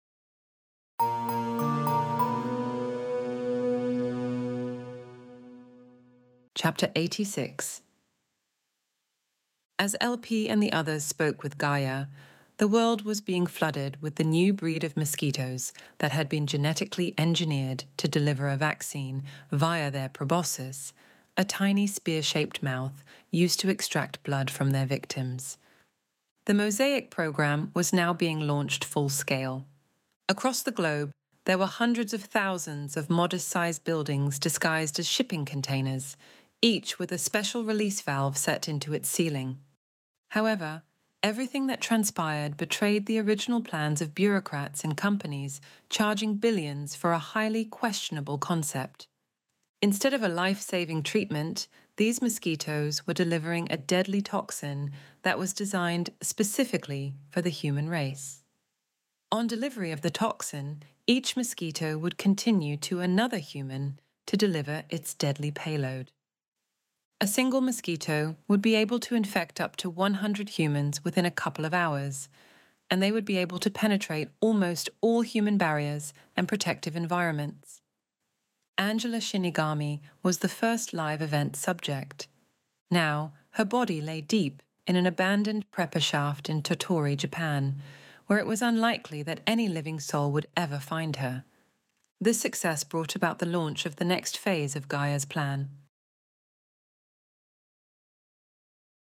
Extinction Event Audiobook Chapter 86